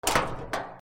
/ K｜フォーリー(開閉) / K05 ｜ドア(扉)
マンション扉 3